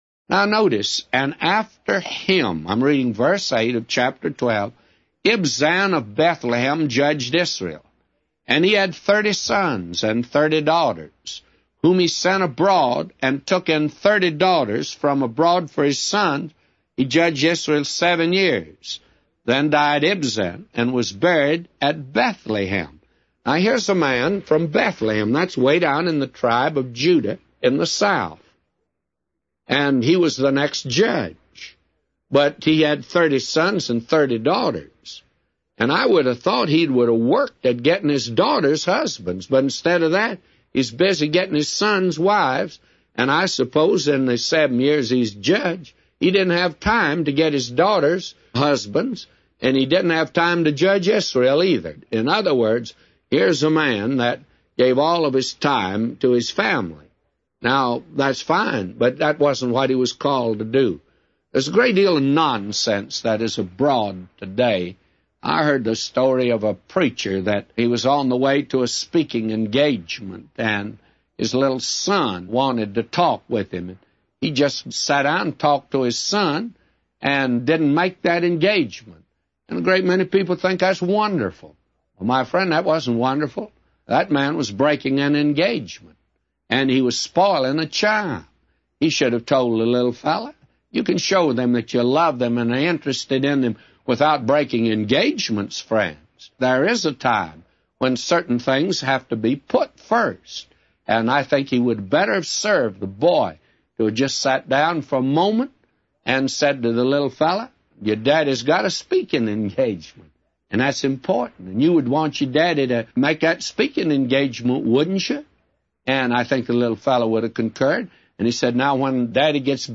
A Commentary By J Vernon MCgee For Judges 12:1-999